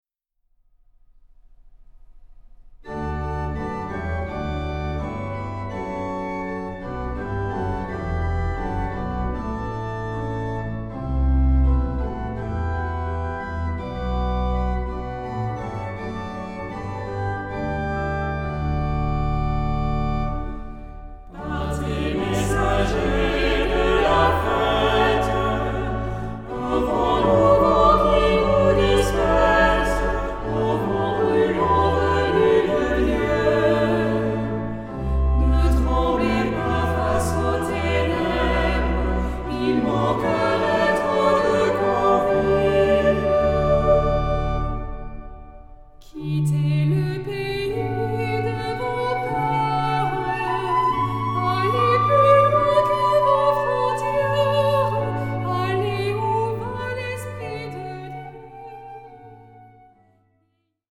Genre-Stil-Form: Kirchenlied ; Motette
Charakter des Stückes: lebhaft
Chorgattung: SATB  (4 gemischter Chor Stimmen )
Instrumente: Orgel (1) ; Melodieinstrument (ad lib)
Tonart(en): D-Dur